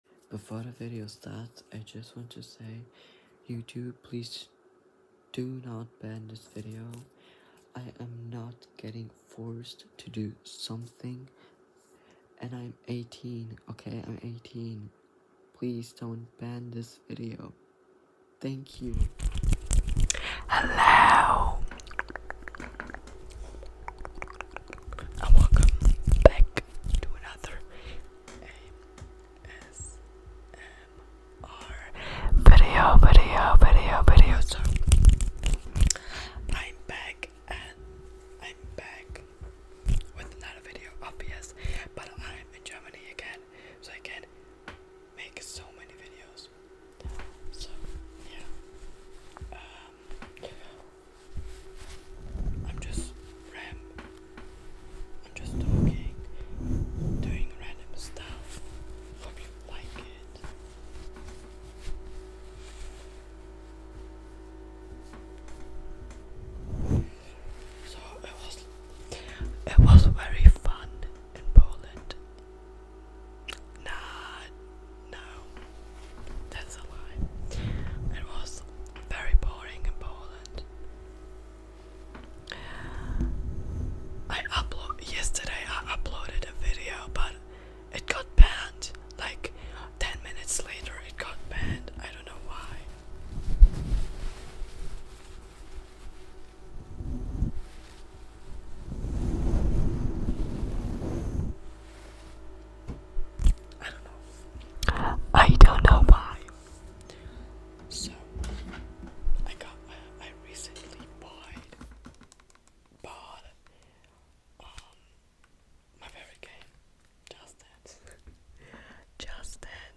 ASMR Random Triggers (Episode 1) by ASMR Triggers (Tapping, ...)